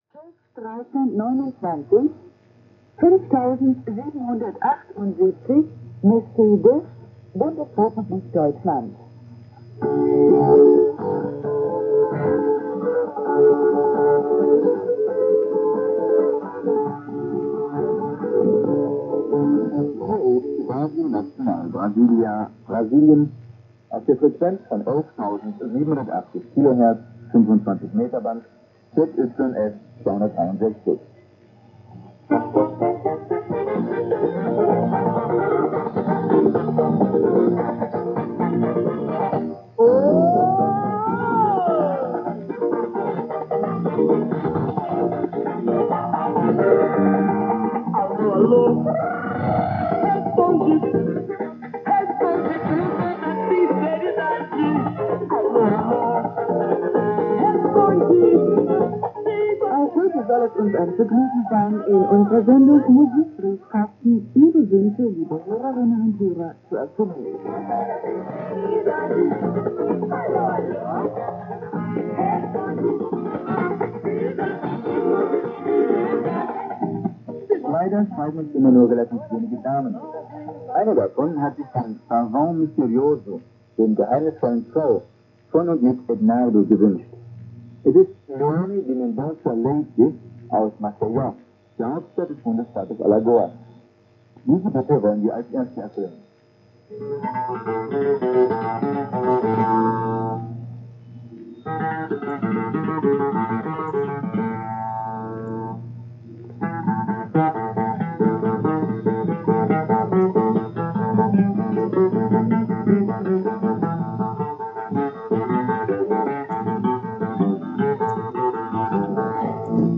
RN do Brasil Brasília / B 11780 kHz - Empfangsort Schwäbisch Gmünd 1978 - Satellit 2000
Rx, Ant: Grundig Satellit 2000 - 30m Langdraht
Px: D, 'Hörerbriefkasten', ID, mx
SINPO: 44444